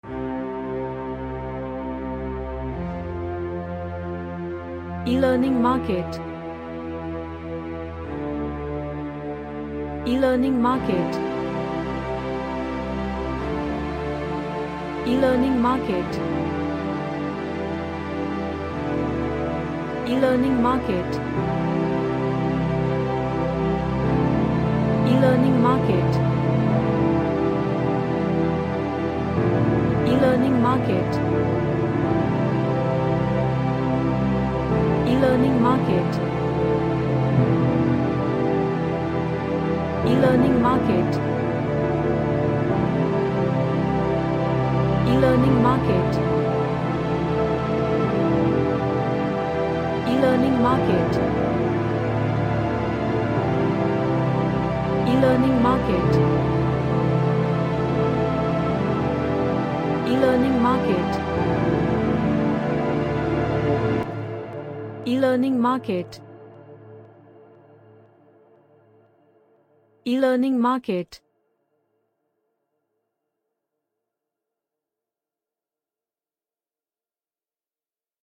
An Orchestral song.
Epic / Orchestral